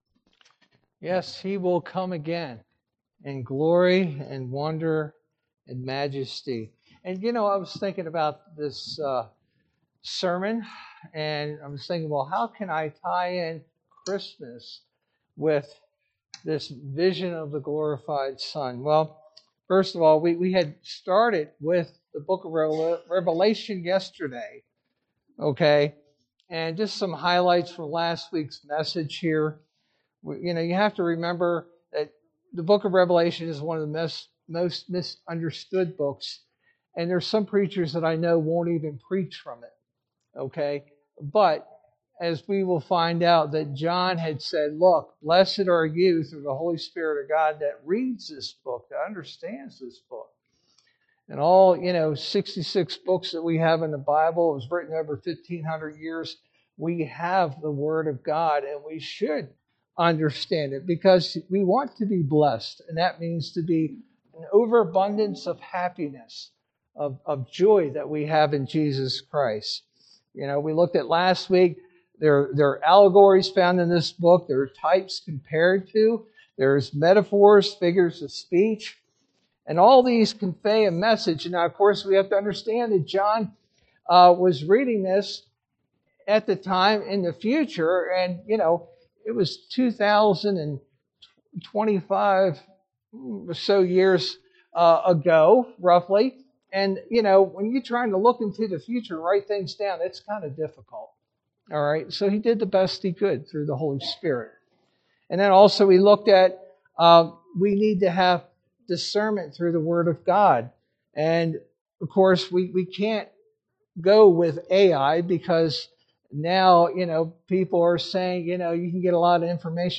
Sermon verse: Revelation 1:9-20